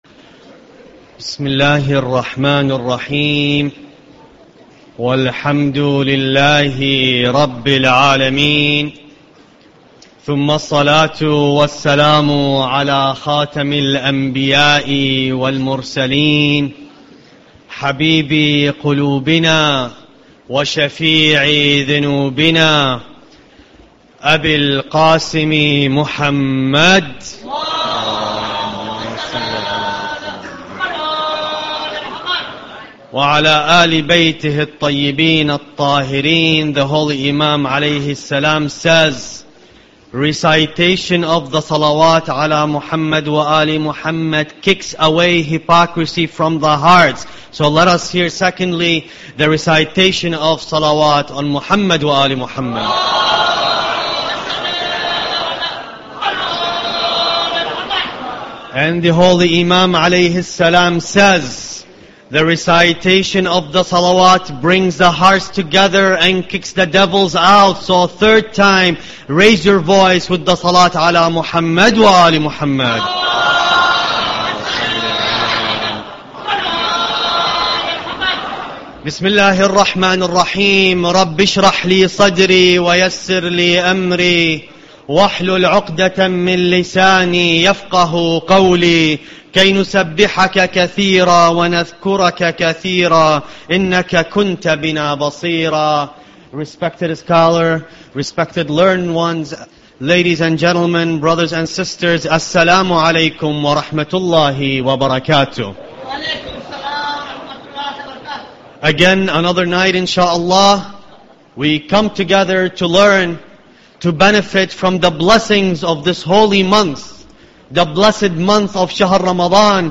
Ramadan Lecture 6